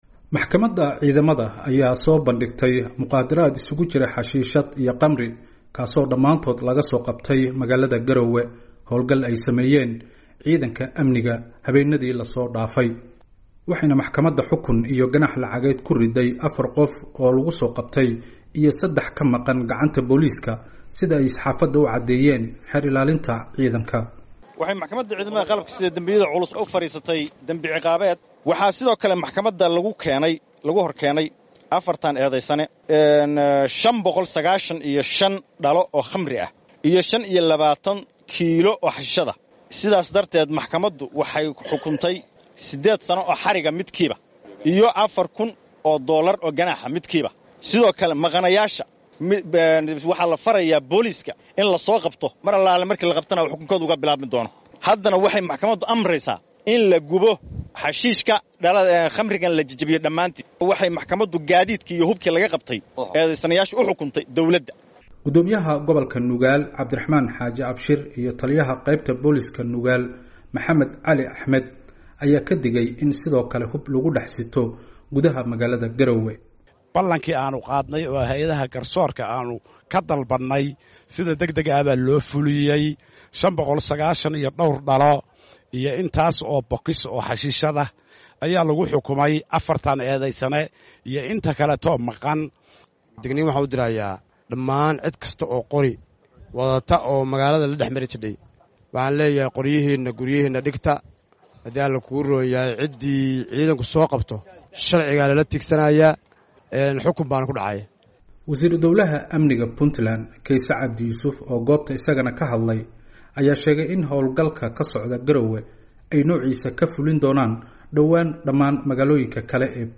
Wariyaha VOA-da